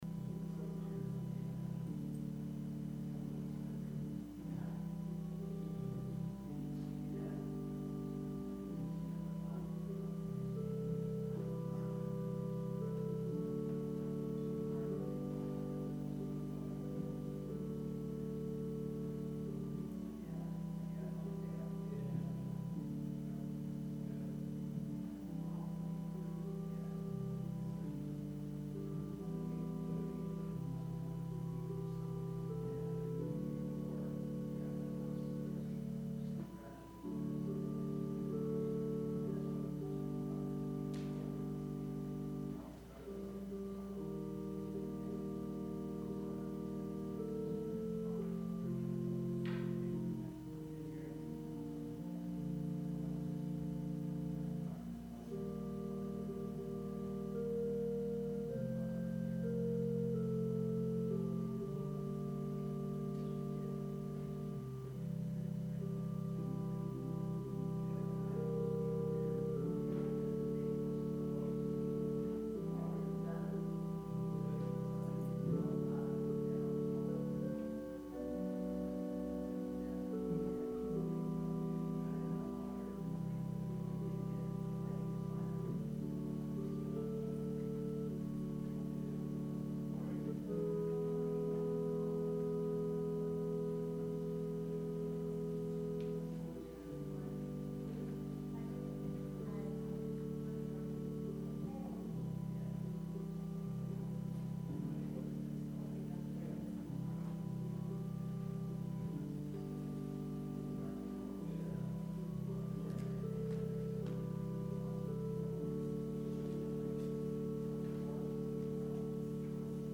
Sermon – December 15, 2019 – Advent Episcopal Church